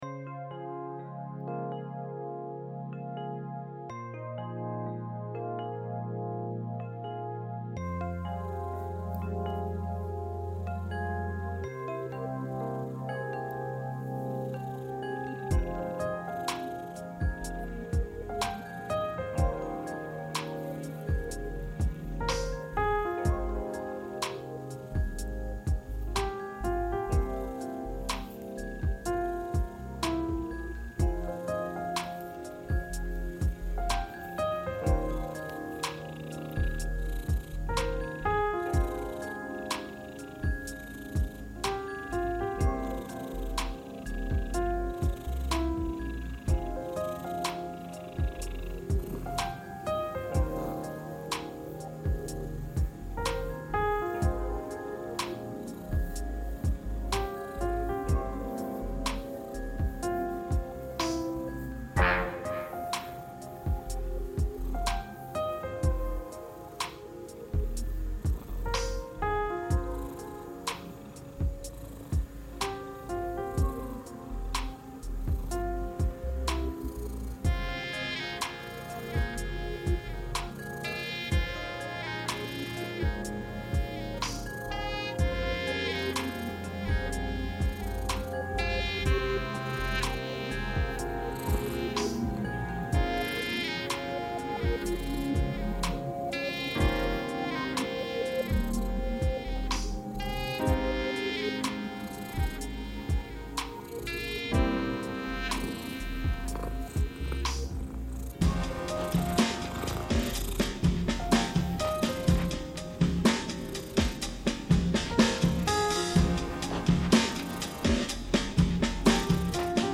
Rescue cat purring reimagined